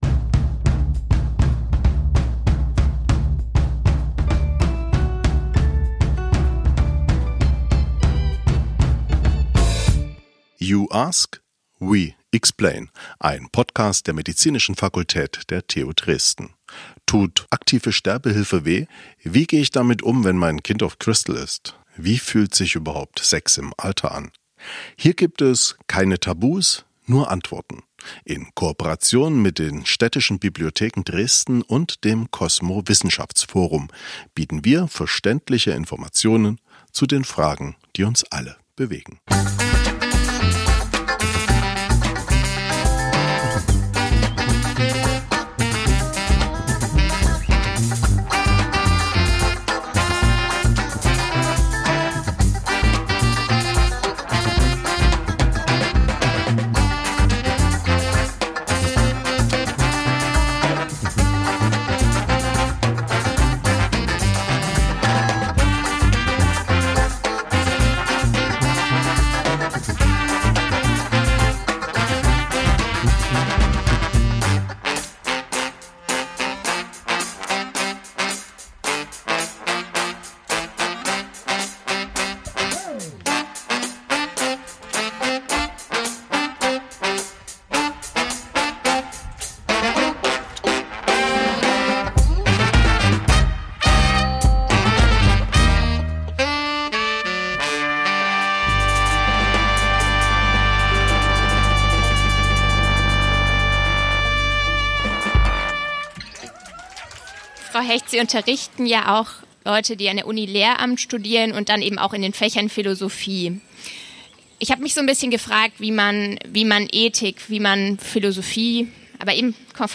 Dazu waren wir auf dem Neustädter Markt im Gespräch mit unseren Expert:innen:  Dr. med. habil...
Musikalische Begleitung von der Banda Comunale Geben Sie uns ihr Feedback- einfach, schnell und anonym.